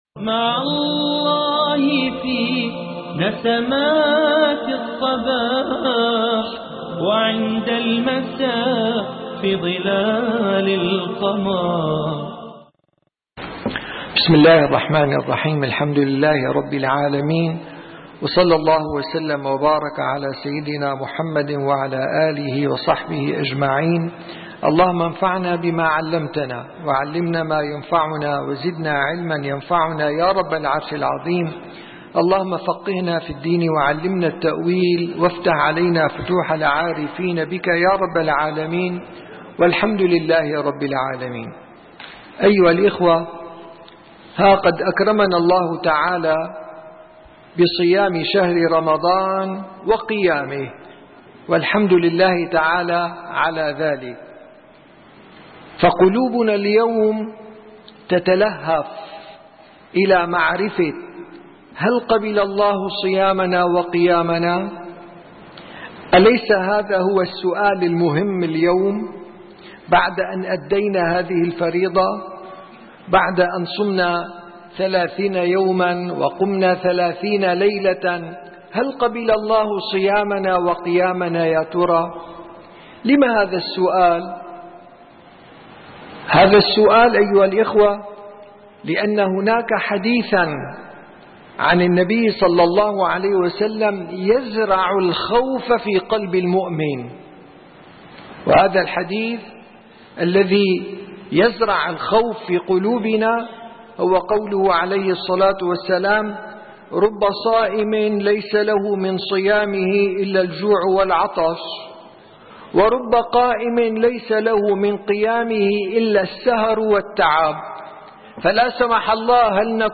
36 - درس جلسة الصفا: هل تقبل الله تعالى صيامك وقيامك